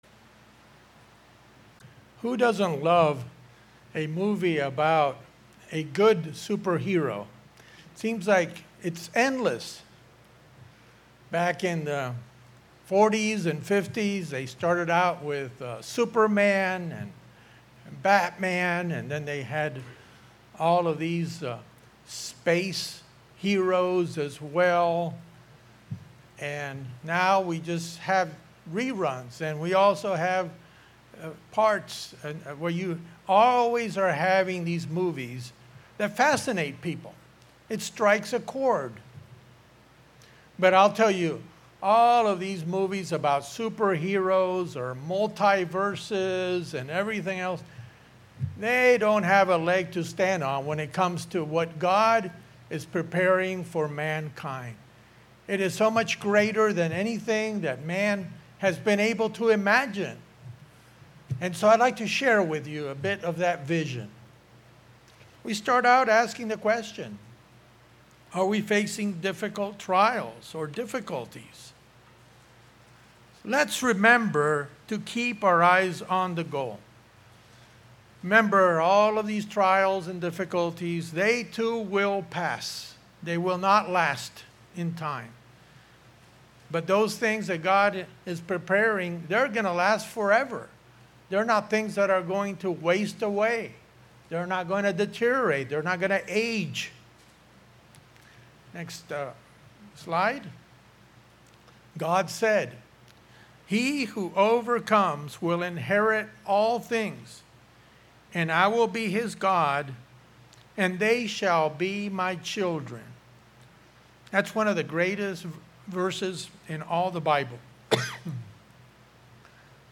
In this PowerPoint message, science continues to prove that God's Creation is so vast as astronomers look into deep space using the James Webb telescope.